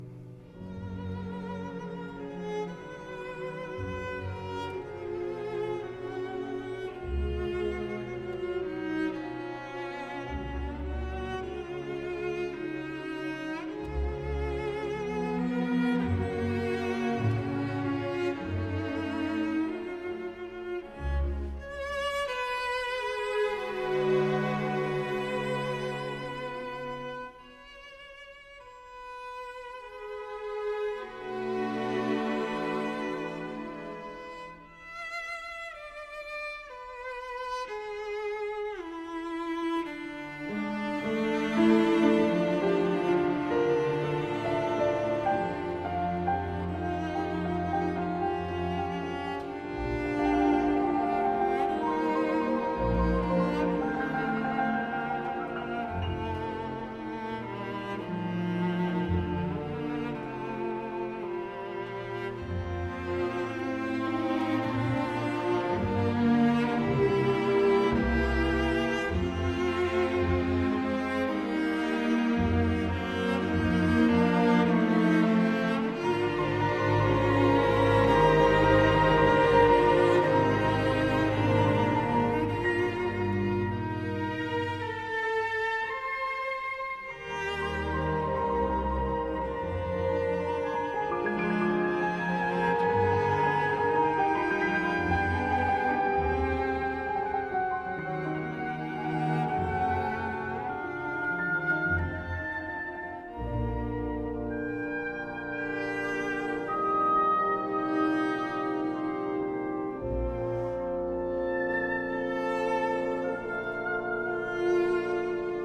Le violoncelle solo revient sur la pointe des pieds, enchaînant p dolce mais pour cette reprise, le piano intervient plus tôt et dans un climat d'apaisement. Les trilles ne sont plus serrés, les arpèges sont calmement énoncés et le dialogue, entre les cordes d'abord, entre le violoncelle solo et le hautbois (en syncopes) ensuite auxquelles se joignent les clarinettes est d'une pure beauté.
Et c'est sereinement que se termine ce moment de rêve.